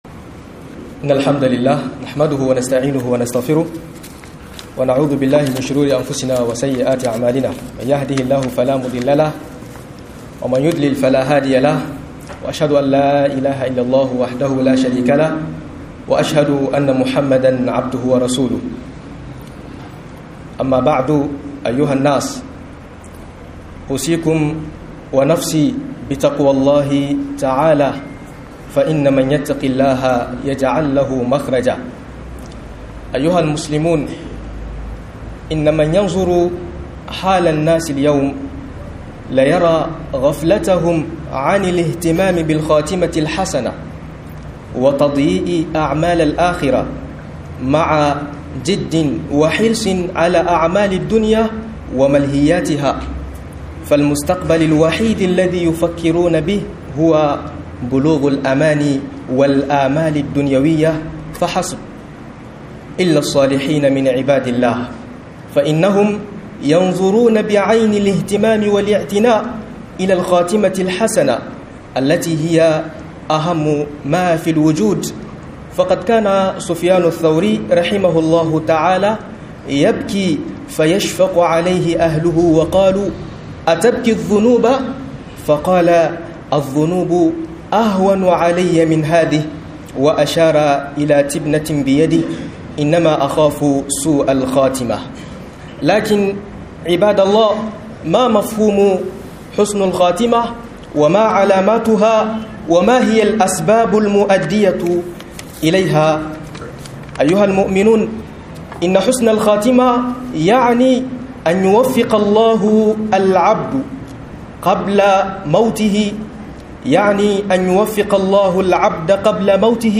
Kew kiyawon Karche - MUHADARA